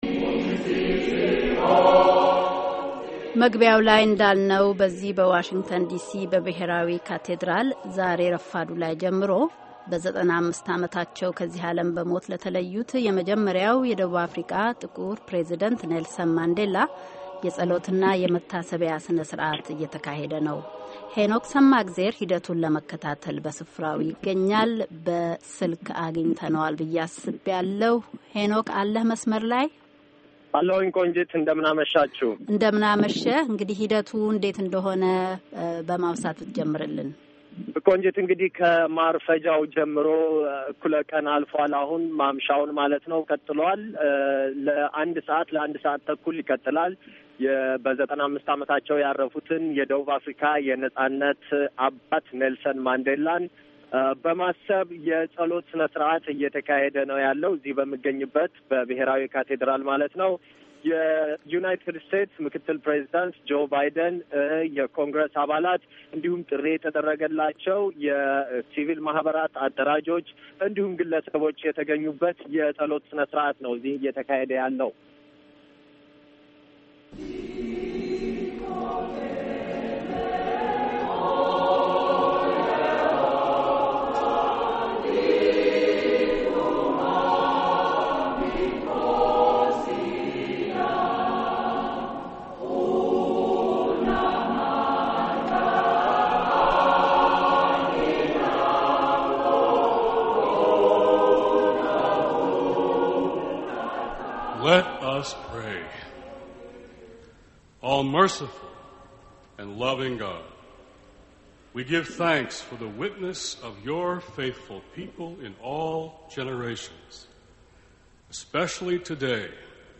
በብሔራዊ ካቴድራል በተካሄደው የማንዴላ መታሰቢያና የፀሎት ሥነ-ሥርዓት ላይ ንግግር ያደረጉት የዩናይትድ ስቴትስ ምክትል ፕሬዚዳንት ጆ ባይደን